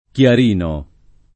chiarino [ k L ar & no ]